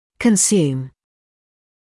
[kən’sjuːm][кэн’сйуːм]потреблять